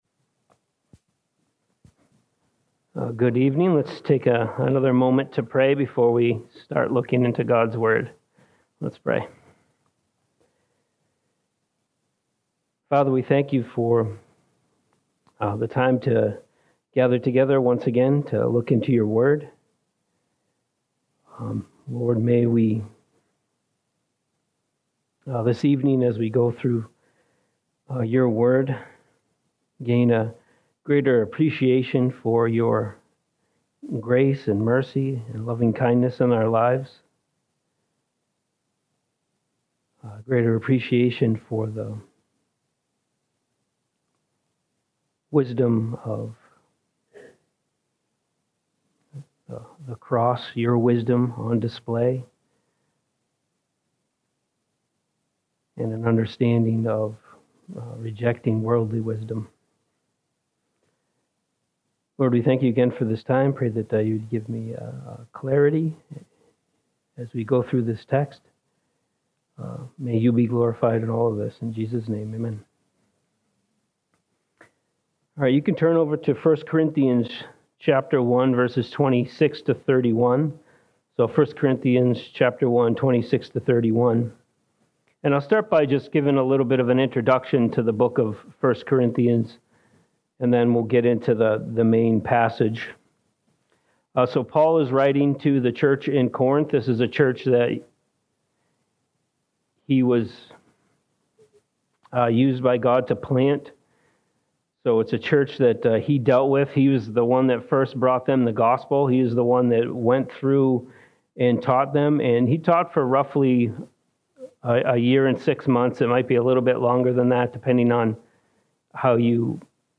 Sunday Morning - Fellowship Bible Church